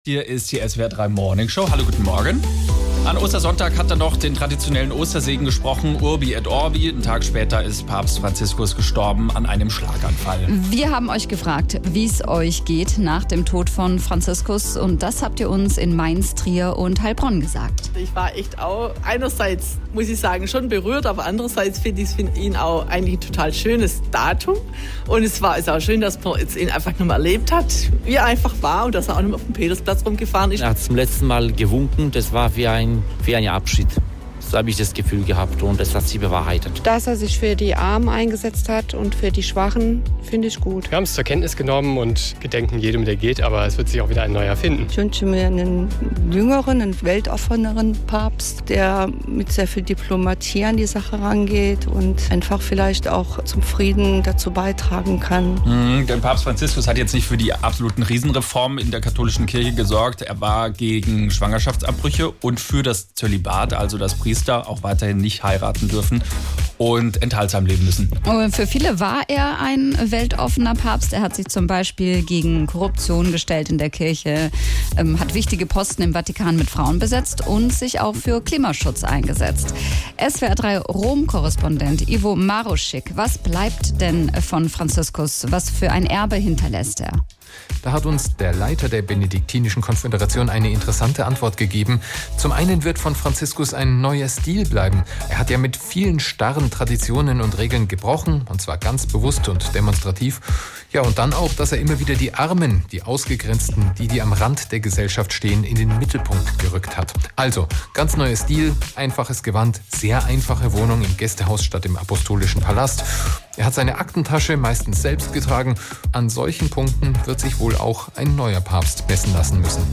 In der Morningshow haben wir gefragt: Wie fühlt ihr euch nach demk Tod des Papstes. Und: Was wird von ihm bleiben?